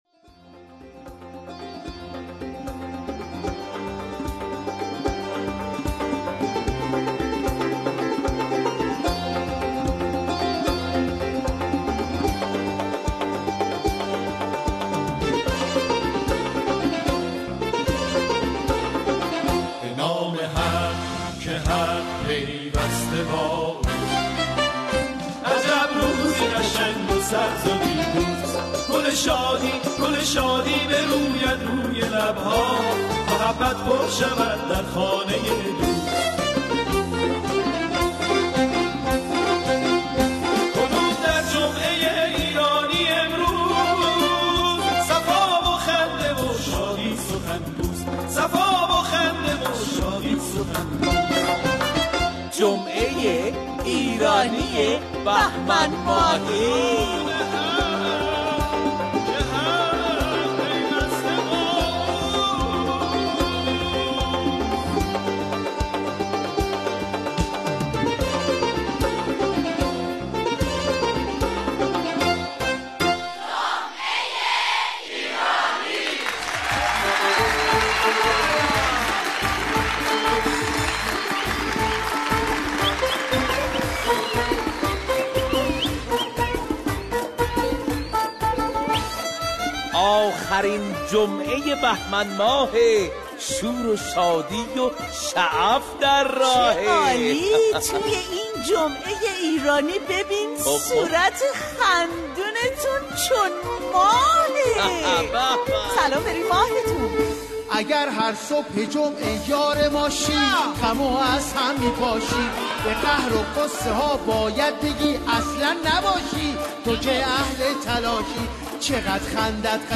ترانه های شاد
برنامه طنز رادیو ایران